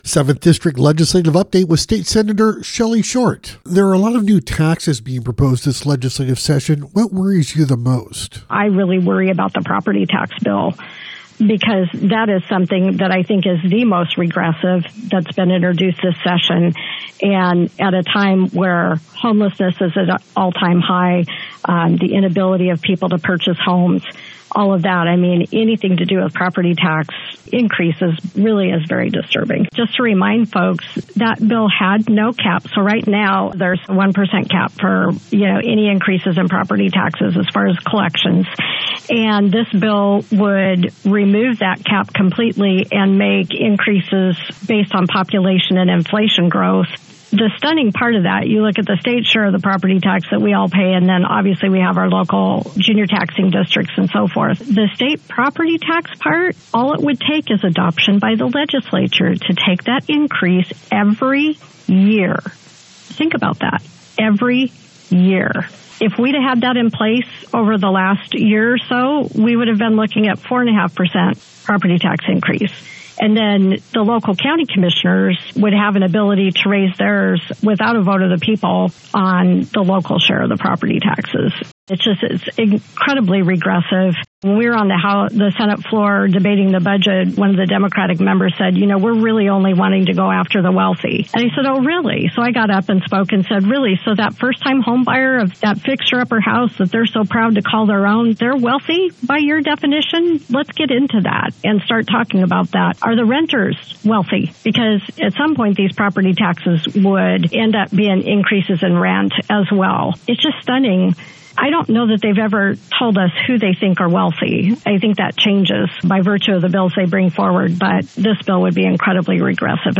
7th District Legislative update with State Senator Shelly Short—Property Taxes - Senate Republican Caucus